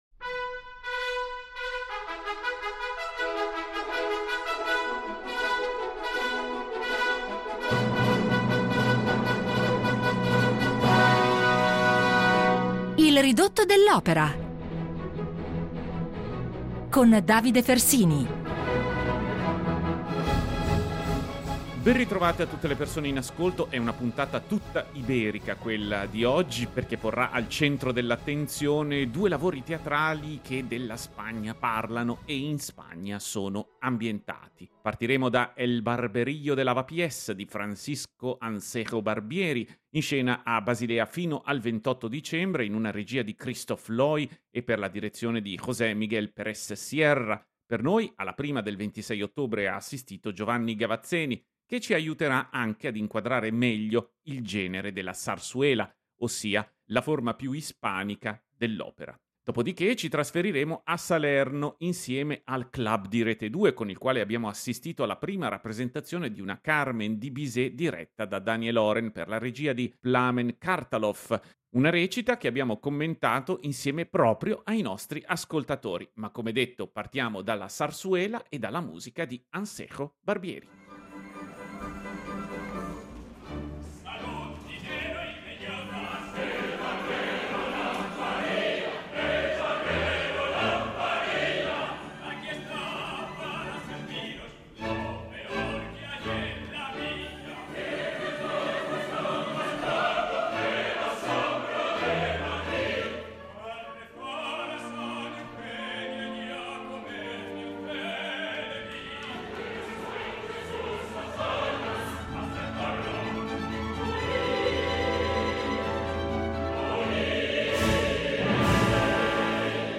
C’è un genere di teatro musicale, nato e cresciuto tra i confini spagnoli, che deve la propria fama alla fortissima caratterizzazione iberica intorno a cui ruotano le sue trame – comicamente serie o drammaticamente giocose - e la sua musica, sempre divisa fra ritmi serrati e avvolgente lirismo.